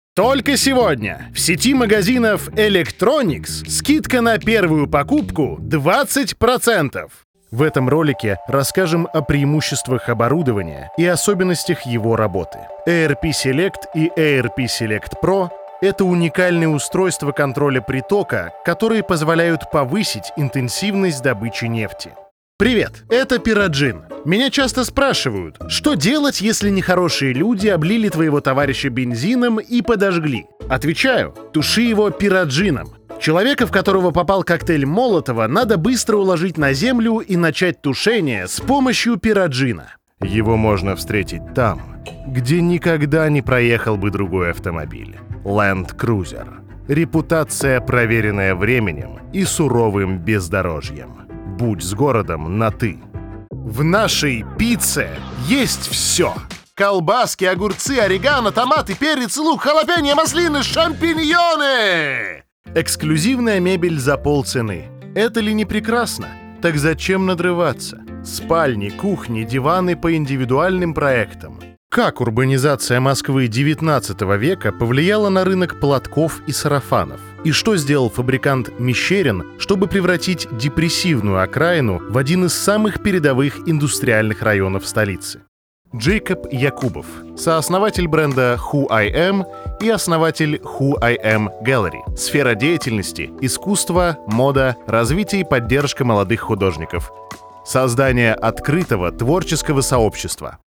Муж, Рекламный ролик/Средний
Живой, естественный голос с широким спектром возможностей для разных задач.
Акустически подготовленное помещение с качественным оборудованием.